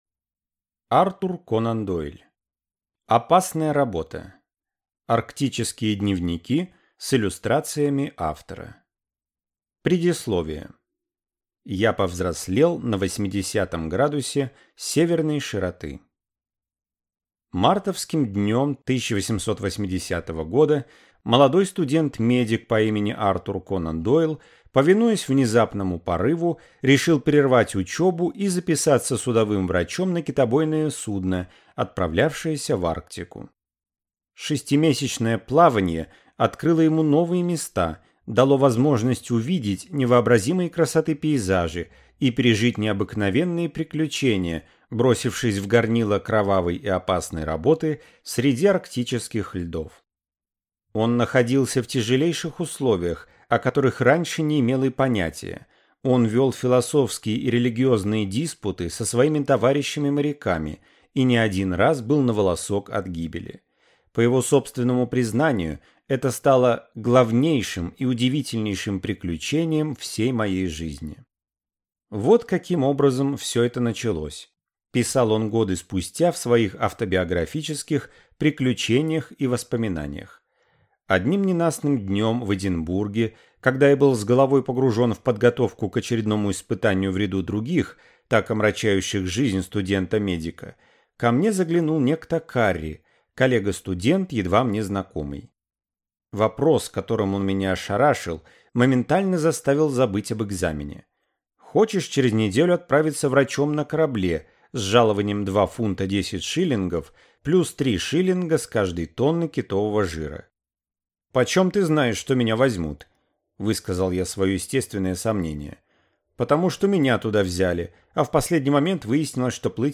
Аудиокнига Опасная работа | Библиотека аудиокниг